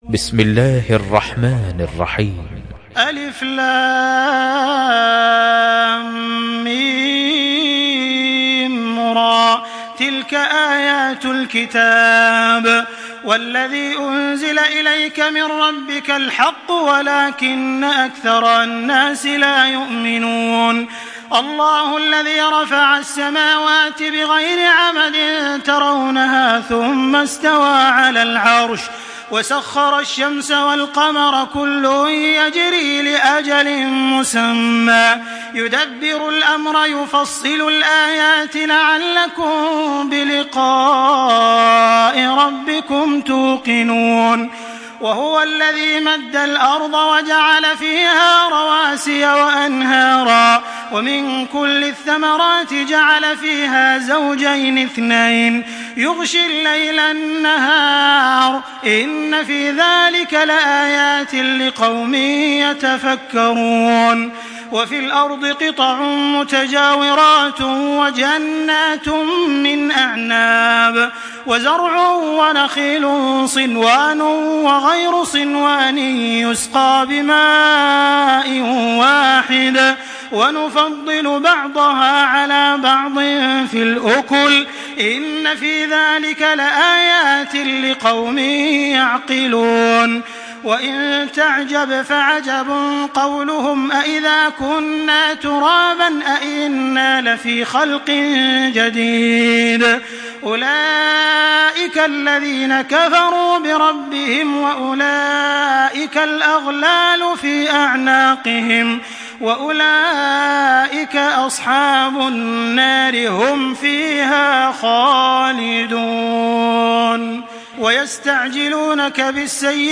Surah আর-রা‘দ MP3 by Makkah Taraweeh 1425 in Hafs An Asim narration.